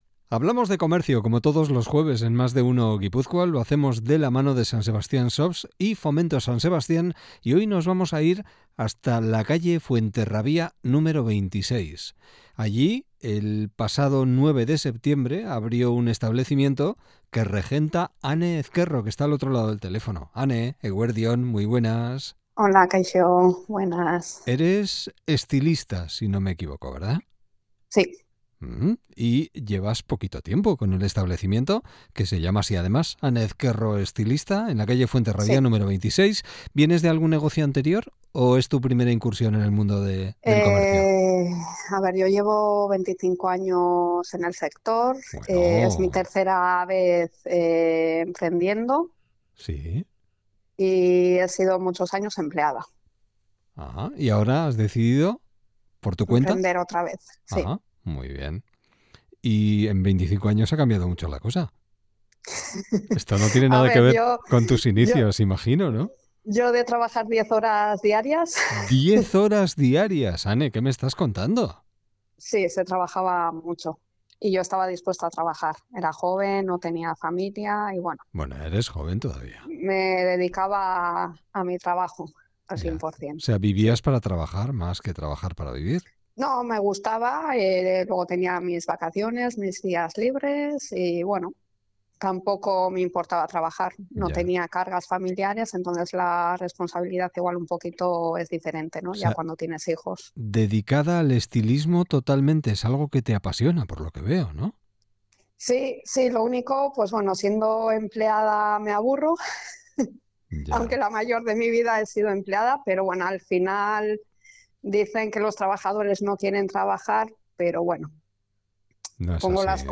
ENTREVISTA
No te pierdas la entrevista completa: